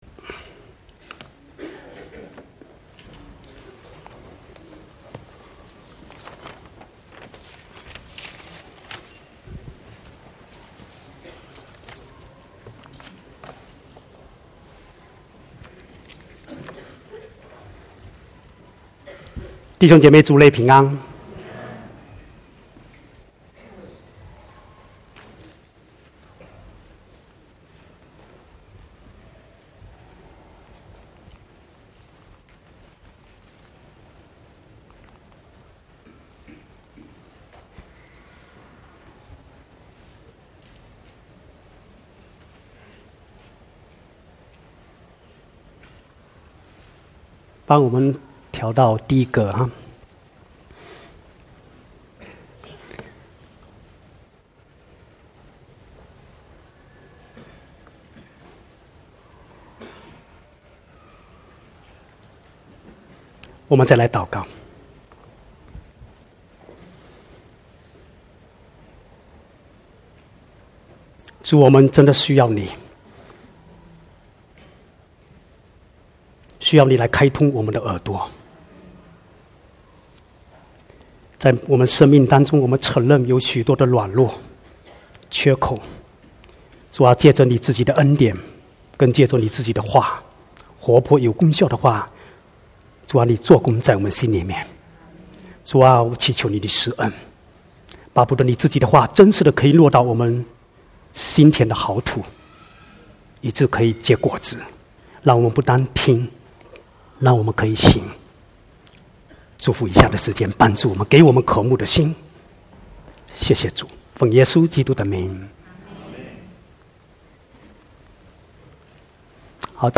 14/7/2019 國語堂講道